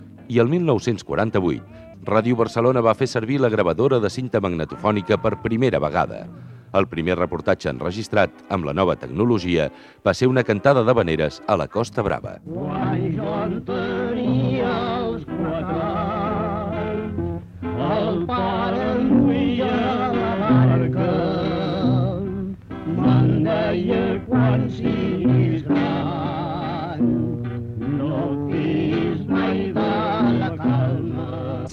Cantada d’havaneres enregistrada en magnetòfon de cinta el 1948.